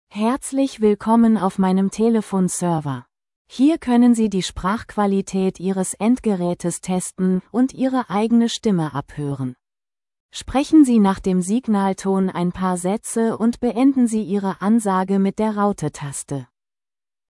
Klangbeispiel eines deutschen Textes als MP3: Die Stimme ist bei Google gTTS immer weiblich.